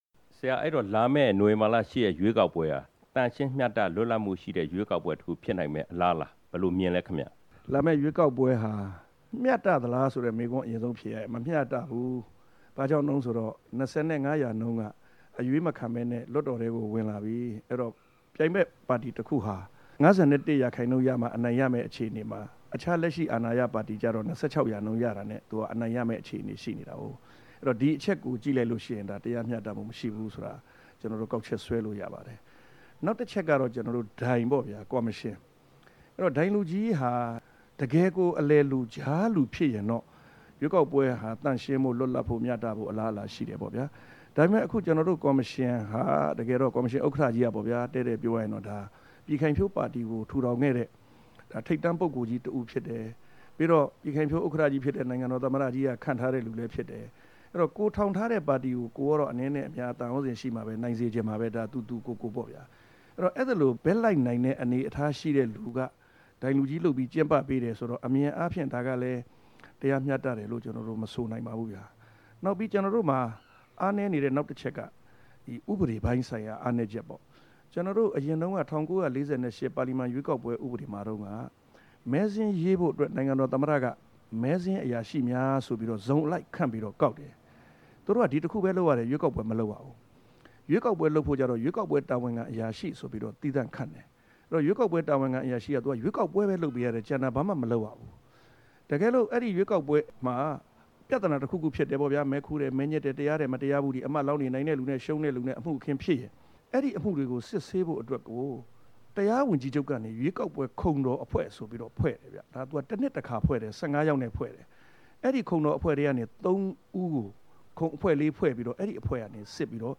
မေးမြန်းချက် အပိုင်း(၃)